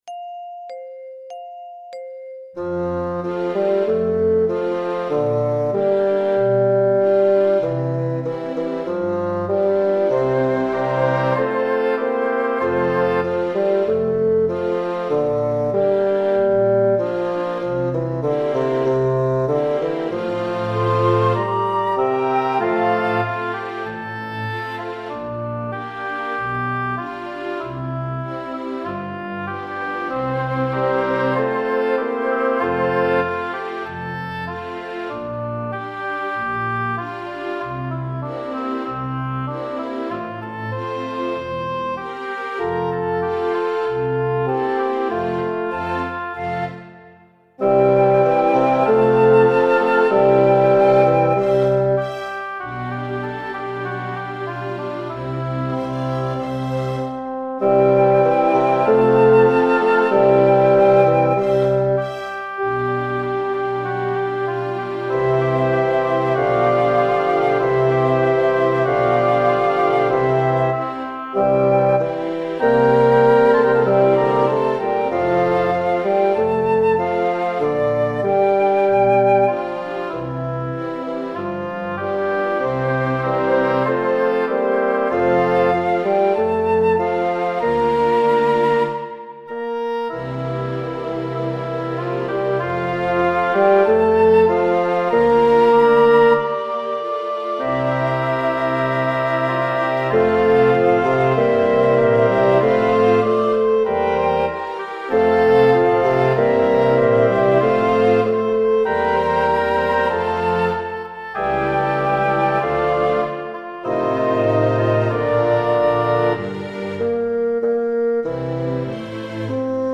Il celebre duetto tra Don Giovanni e Zerlina Là ci darem la mano, qui proposto per due flauti.